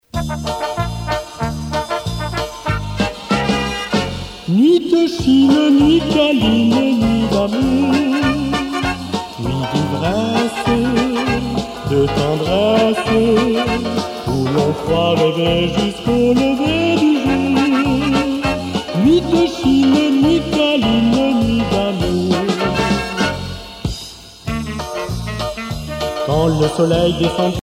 danse : charleston
Pièce musicale éditée